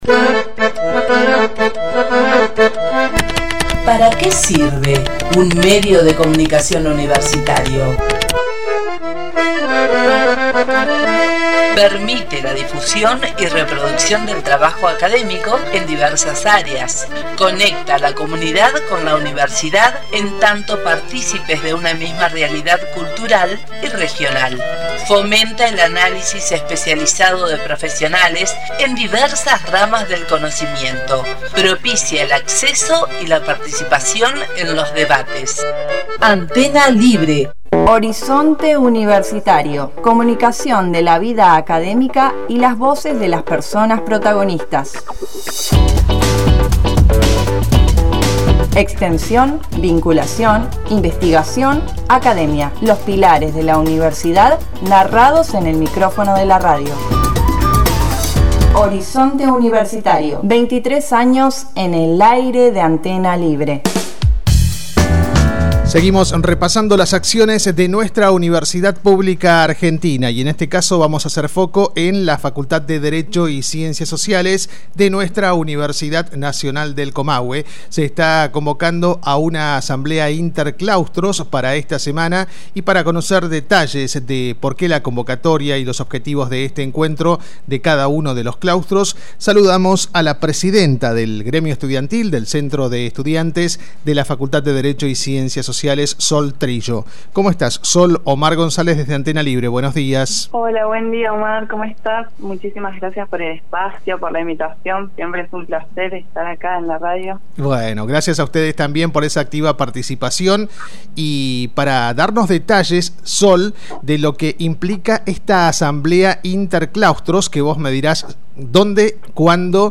En diálogo con Horizonte Universitario